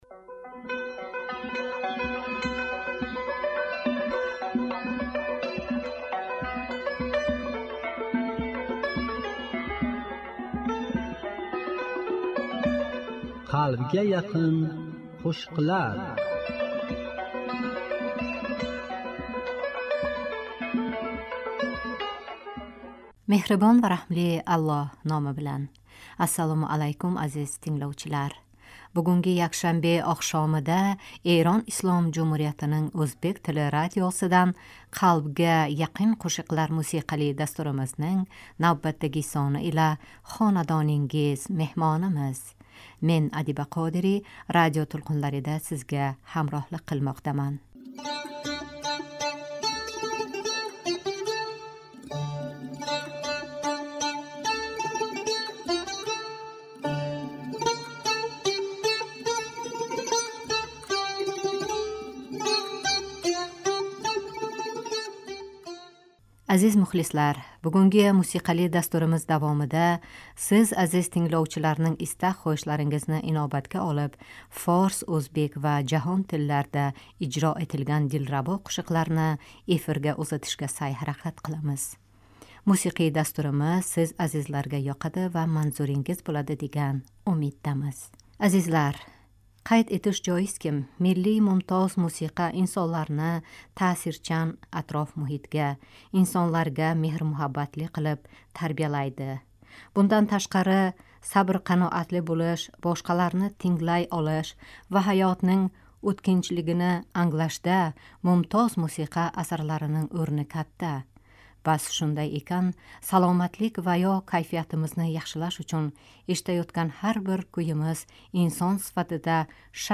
Форс, ўзбек ва жаҳон тилларда ижро этилган дилрабо қўшиқлар